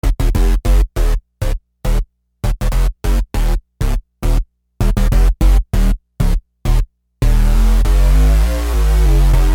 Bass 17.wav